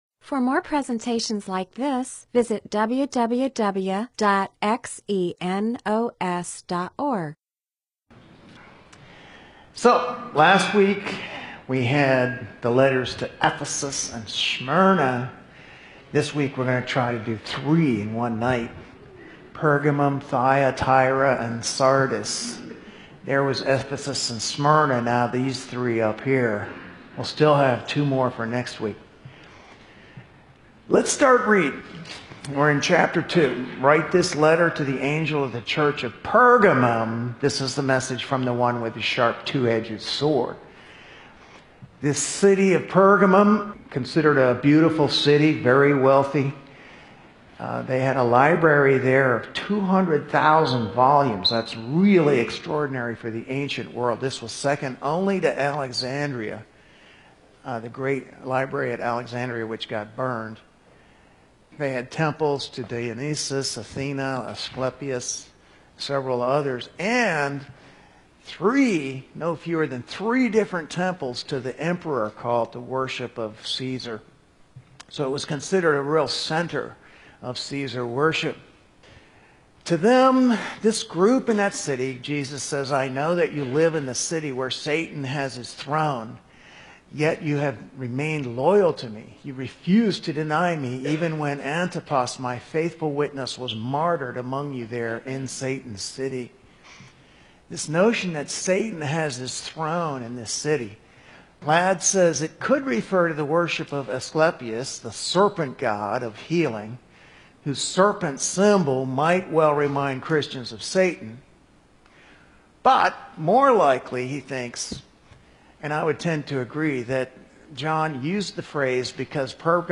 MP4/M4A audio recording of a Bible teaching/sermon/presentation about Revelation 2:12-3:5.